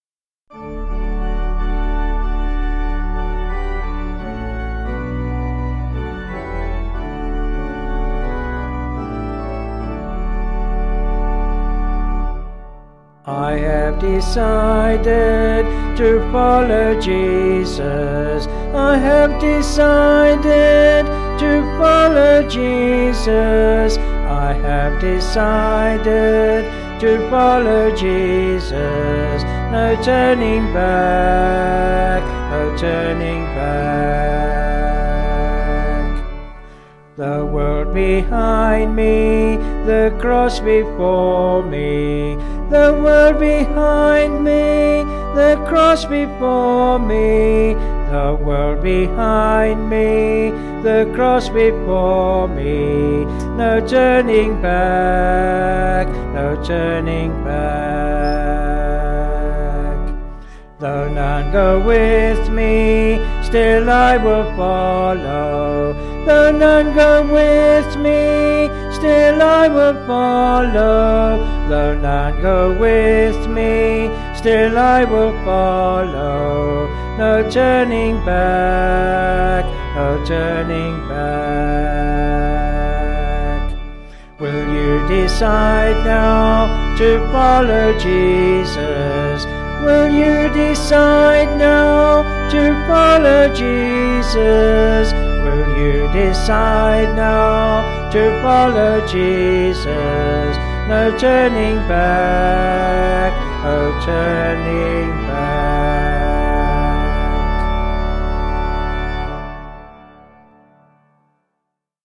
Vocals and Organ   263.7kb Sung Lyrics